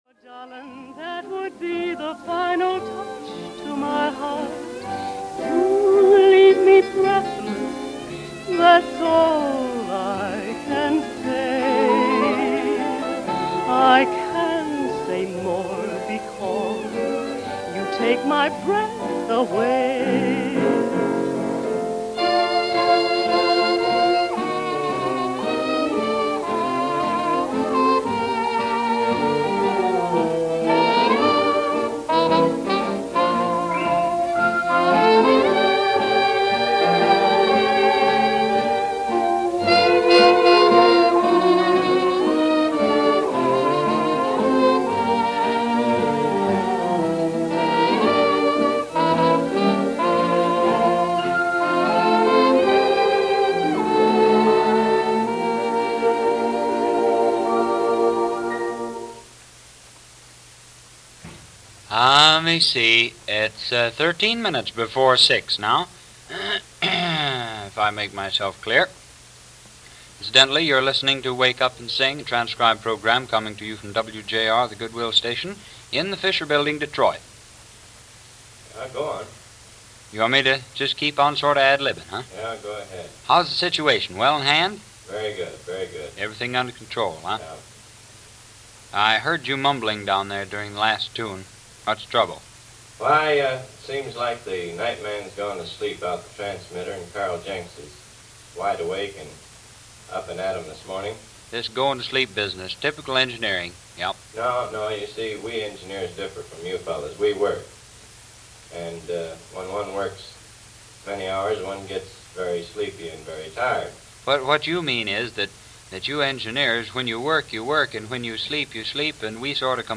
Or there was one in the kitchen and a program, just like this one from WJR in Detroit was covering the background while you ate breakfast.